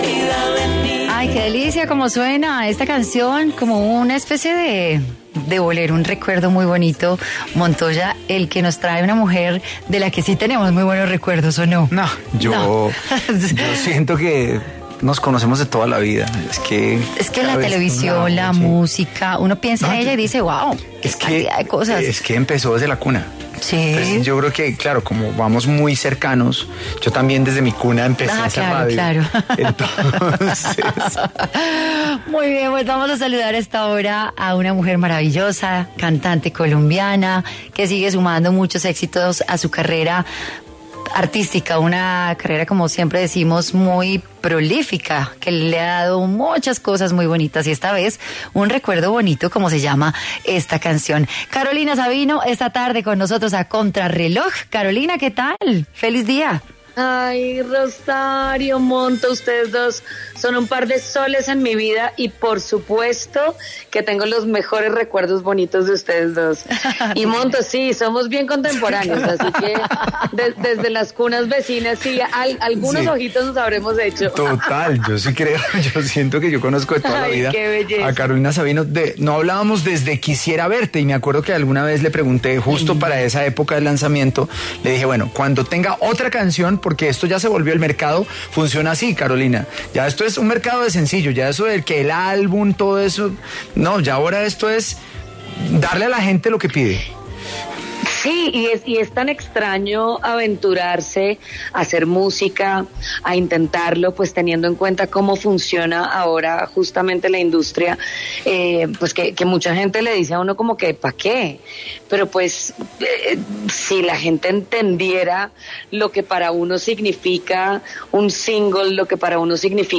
Carolina Sabino, cantante colombiana, habló para Contrarreloj sobre su nuevo sencillo estrenado el 25 de marzo.